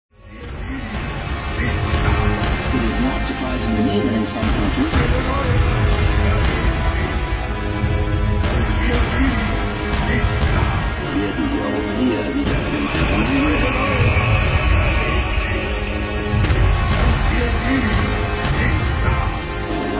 'dark neo-classical/industrial'